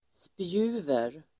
Ladda ner uttalet
spjuver.mp3